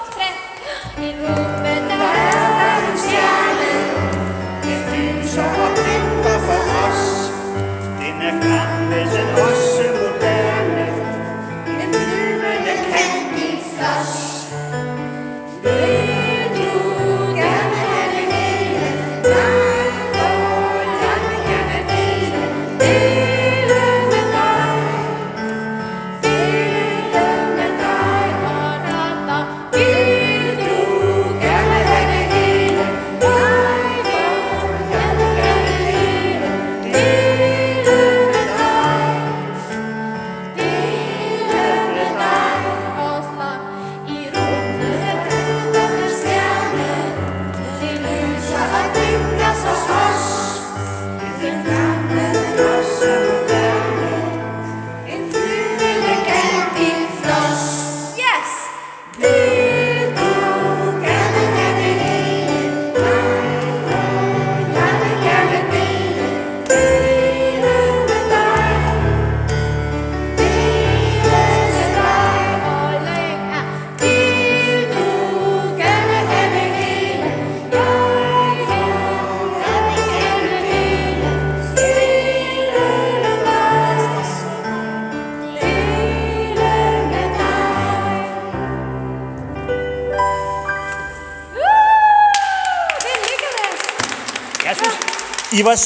Hør "En flyvende Candyfloss" med Børnetjenesten i Messiaskirken.